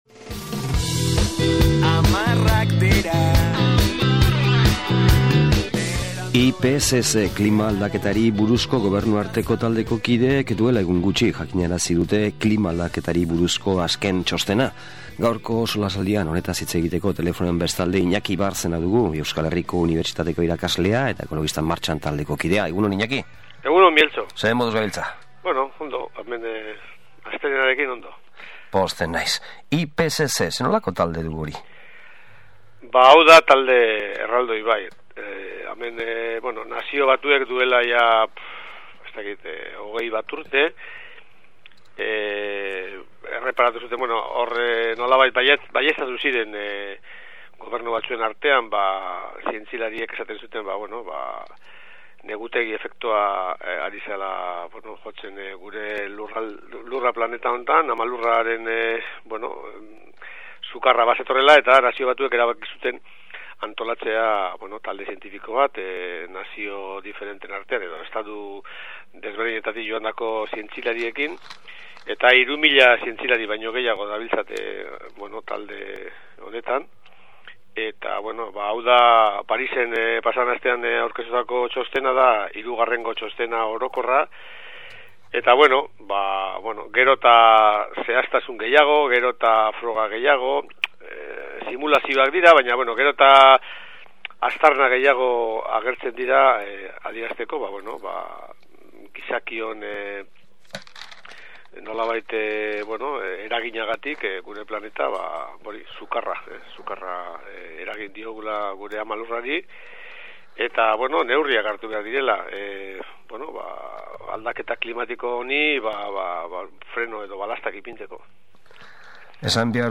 SOLASALDIA: Parisko txostena